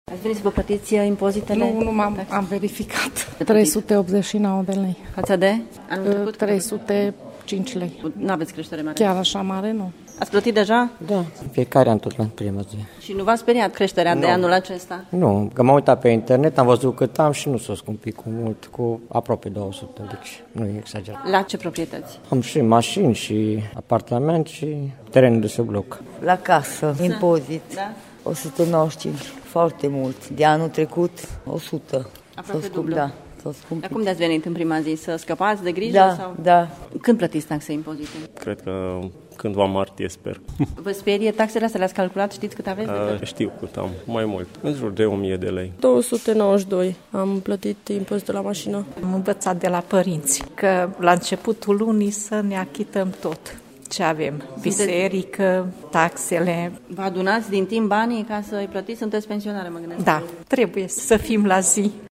Serviciul de încasări impozite și taxe de la sediul Primăriei Târgu Mureș, era aproape gol în această dimineață iar mulți dintre cei care au venit la ghișee mai mult se interesau de cât au de plată: